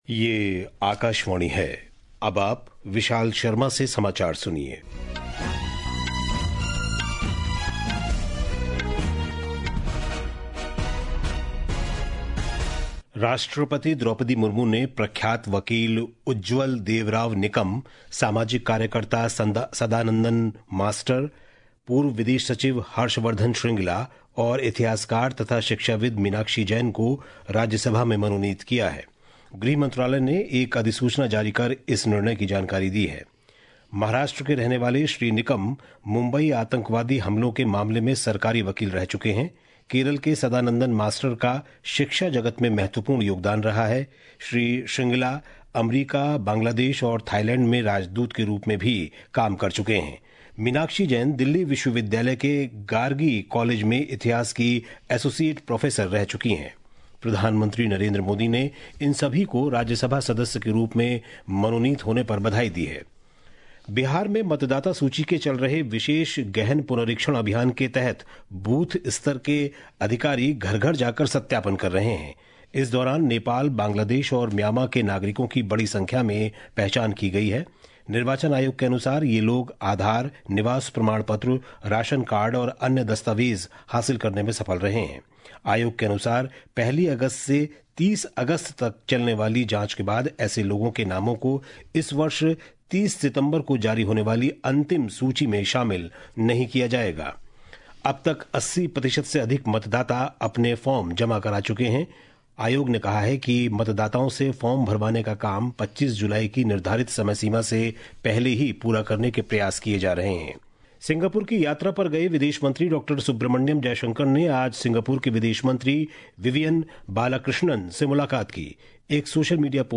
জাতীয় বুলেটিন
प्रति घंटा समाचार